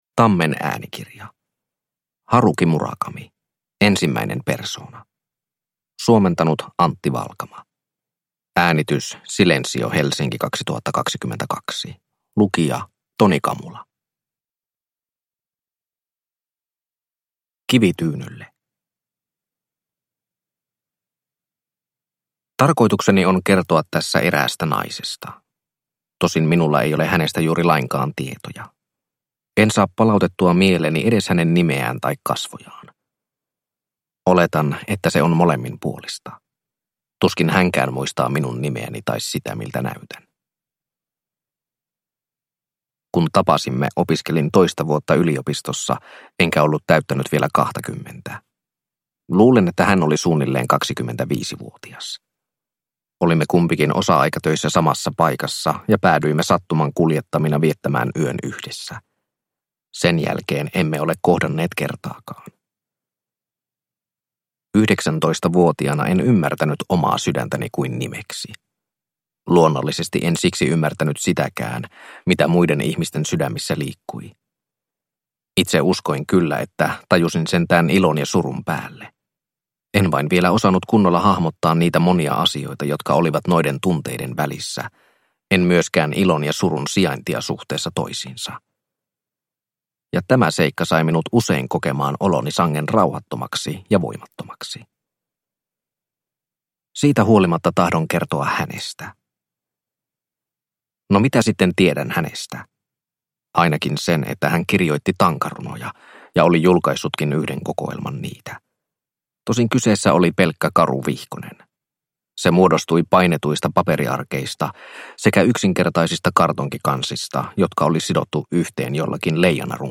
Ensimmäinen persoona – Ljudbok – Laddas ner